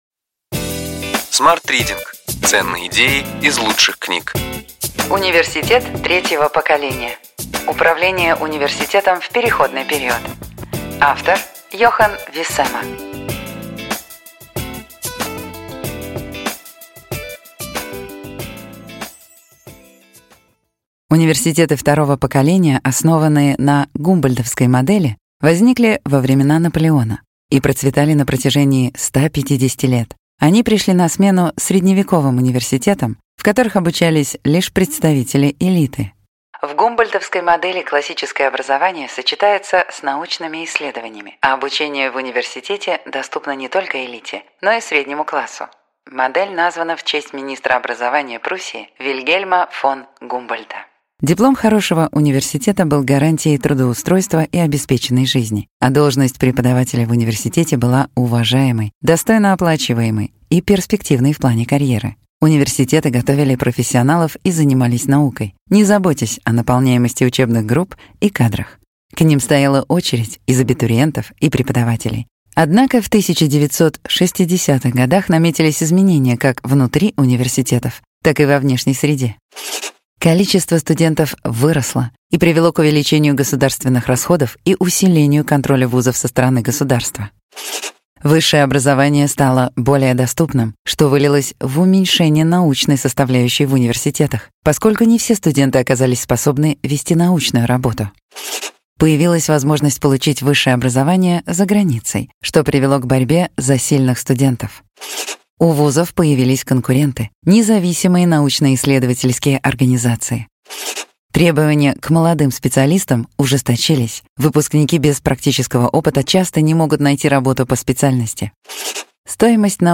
Аудиокнига Ключевые идеи книги: Университет третьего поколения. Управление университетом в переходный период. Йохан Виссема | Библиотека аудиокниг